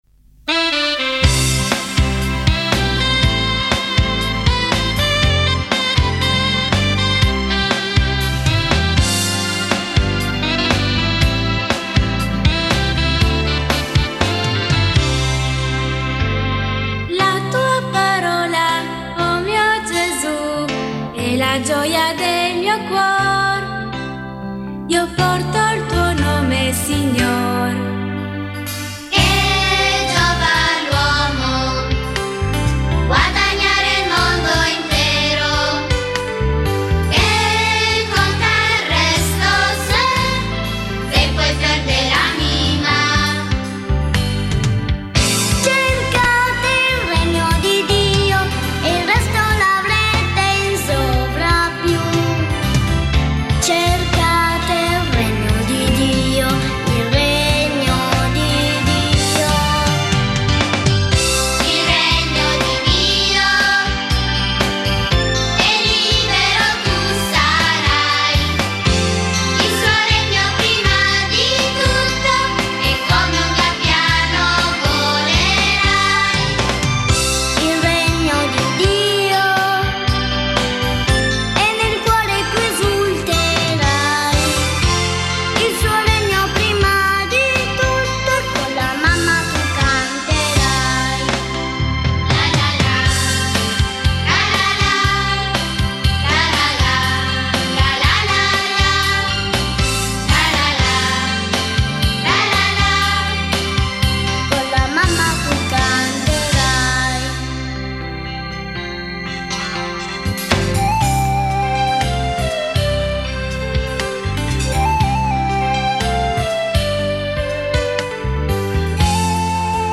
Canto per Rosario e Parola di Dio: Cercate il Regno di Dio